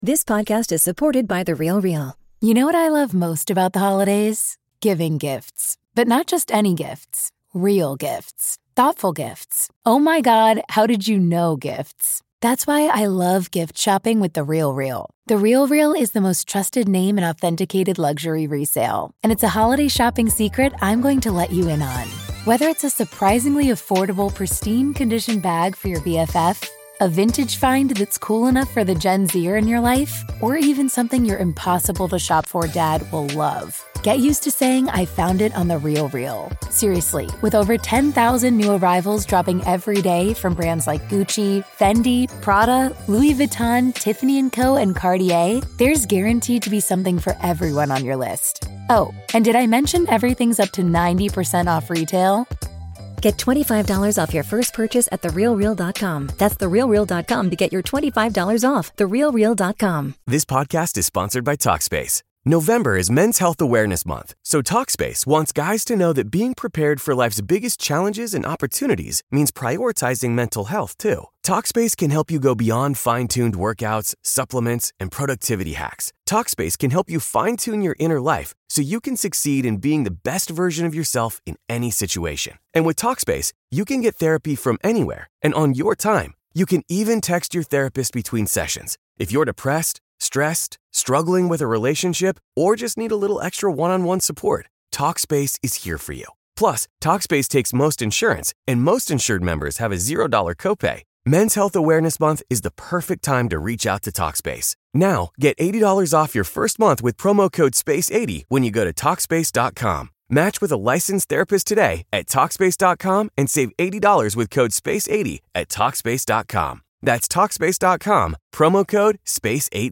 1 DEBATE | Does The Big Bang Reveal God?